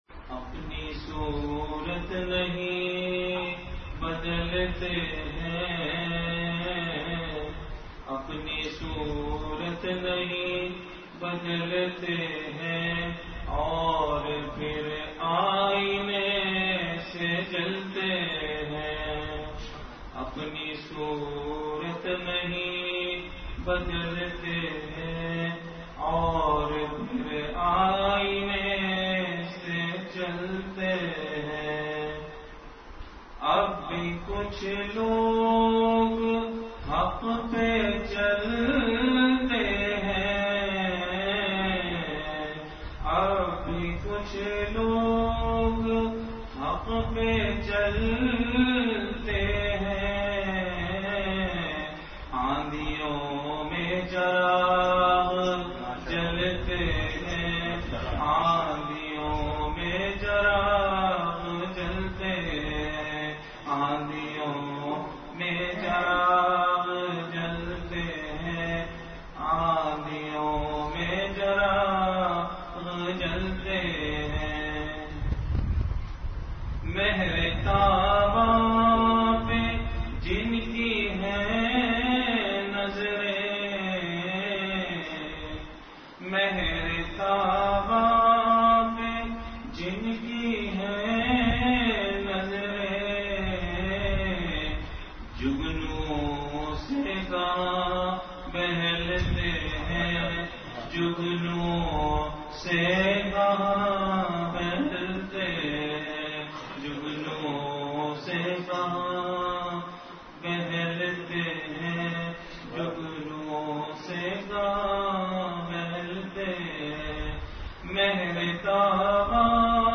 Majlis-e-Zikr